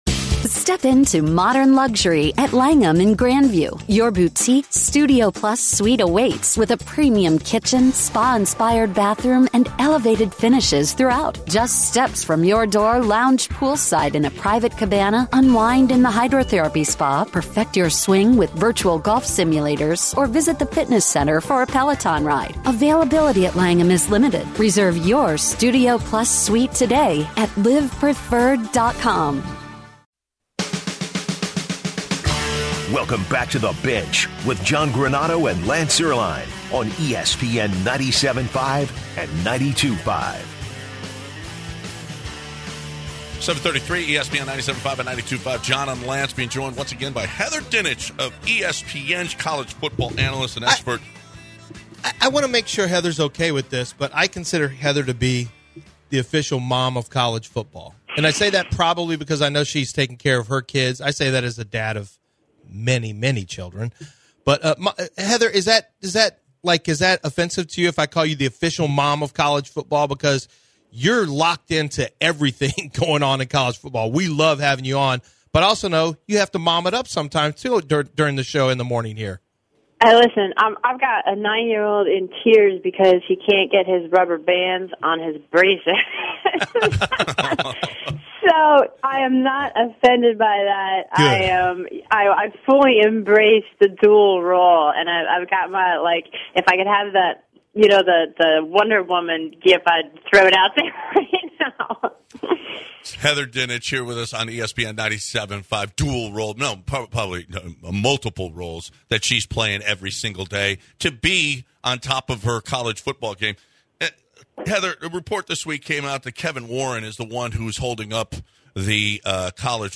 calls in to the show to talk about what is potentially holding up CFP expansion, could expansion help prevent players from opting out along with other aspects.